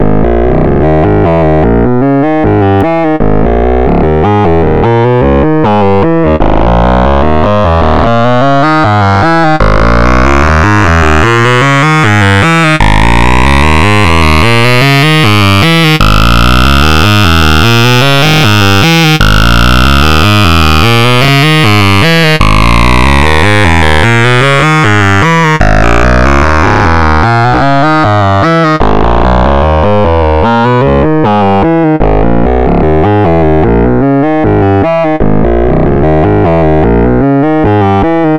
A monster monophonic synth, written in faust.
• 4 oscillators in double crossfade configuration, so iow Vector Synthesis.
This sounds similar yet very different from filtering the output of an oscillator.
• the synth is fully stereo: each parameter has a main slider and a left-right difference slider